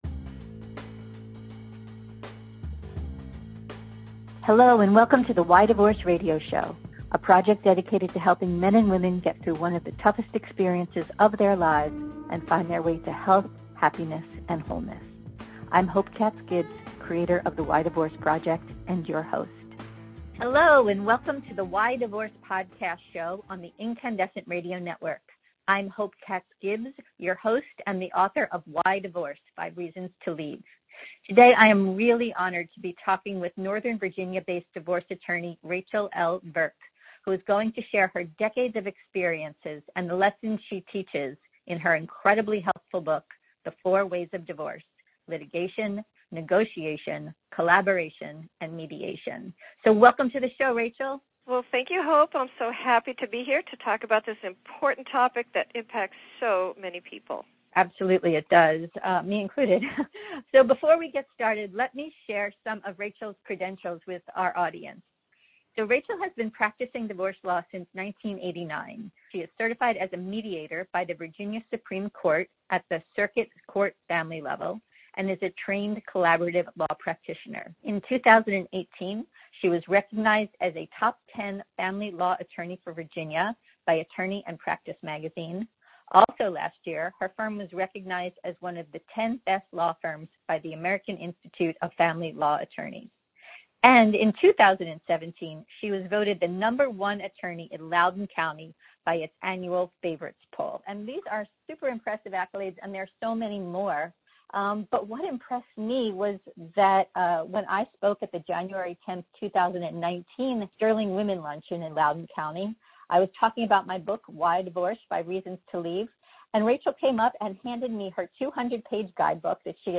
Don’t miss our podcast interview, which will be featured in the book project: Why Divorce: 5 Reasons to Leave.